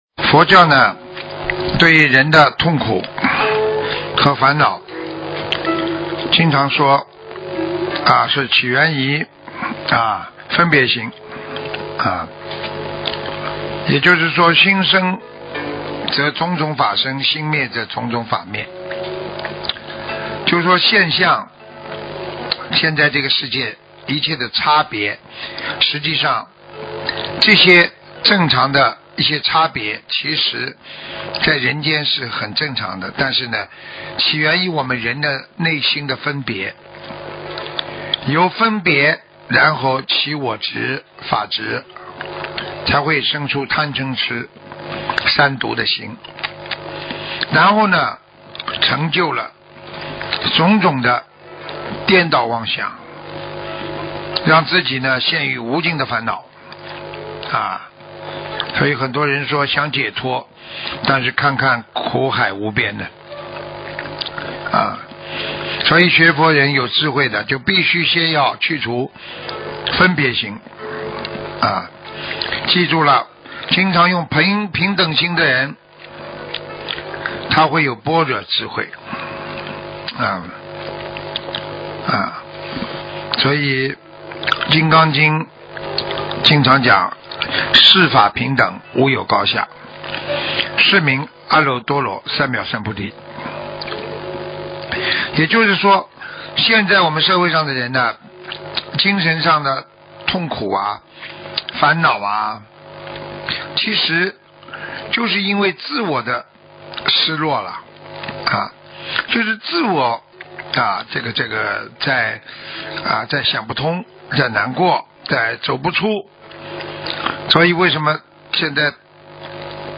睡前一听【BH佛法】171102 经常用平等心的人，他会有般若智慧 * 我们的睡前一听就是师父的广播讲座栏目，合集在此